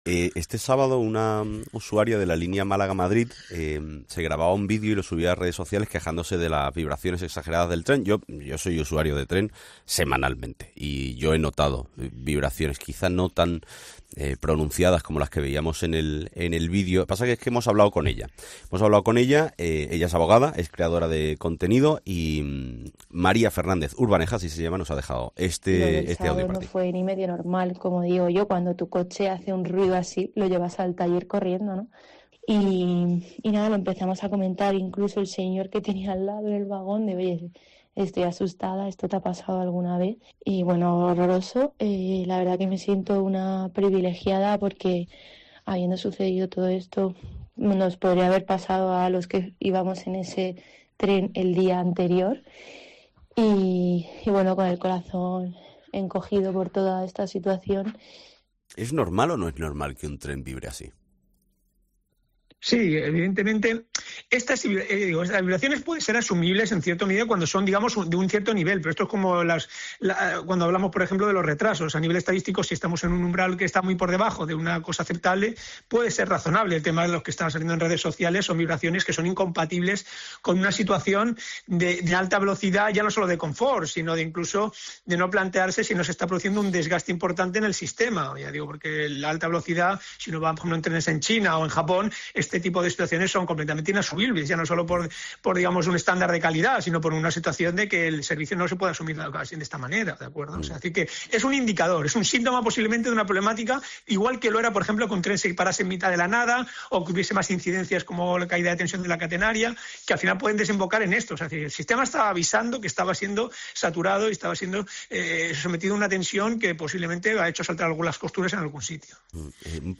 Relata a COPE las vibraciones que notó del tren, llegando a estar asustada.